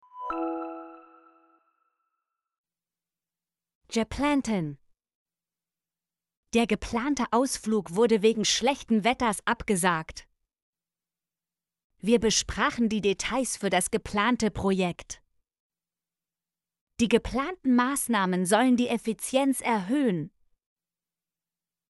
geplanten - Example Sentences & Pronunciation, German Frequency List